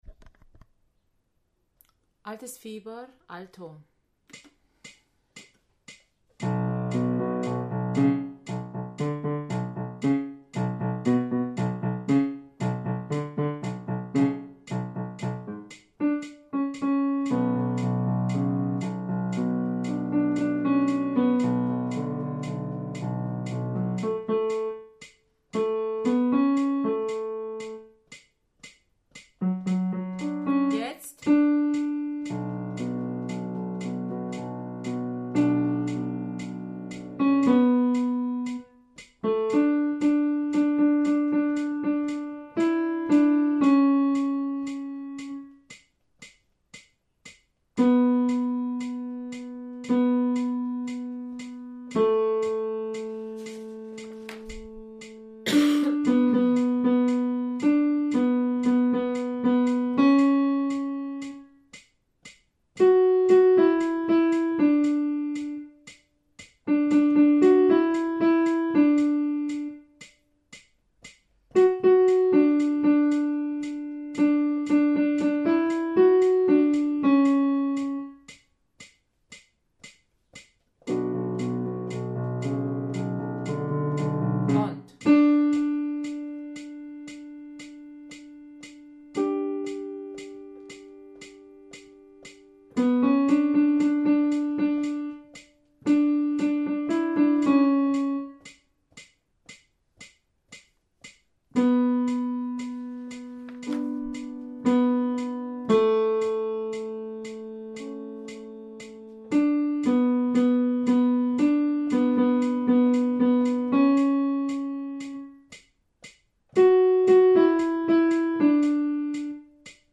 Altes Fieber – Alto
Altes-Fieber-Alto.mp3